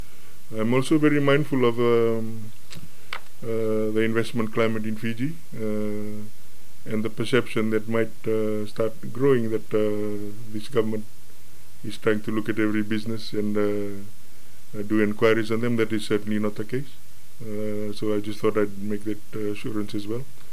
In a press conference this morning, Kamikamica revealed that he was asked by Prime Minister Sitiveni Rabuka to head the investigation.